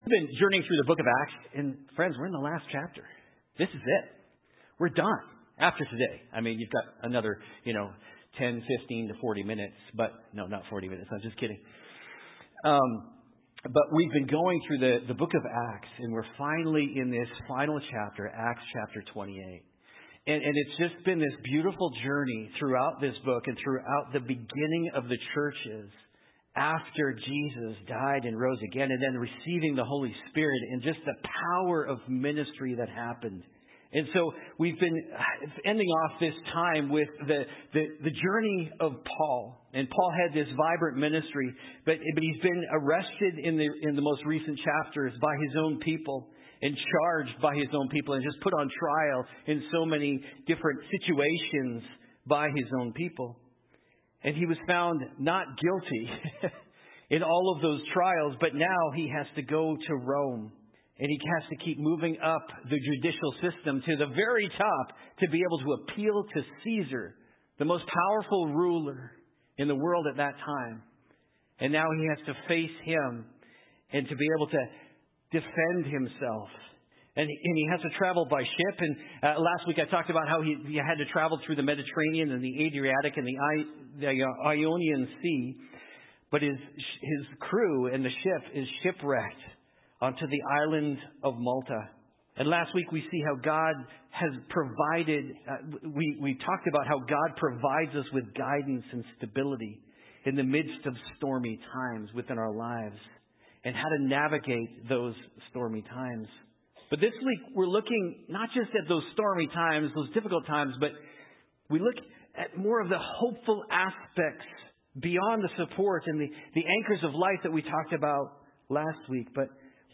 Nov 17, 2024 Hard Times MP3 Notes Sermons in this Series Baptism 101 Hard Times Stormy Times Faith on Trial Living a Life That Doesn’t Make Sense Character Contrast Opportunities What’s Your Jesus Story?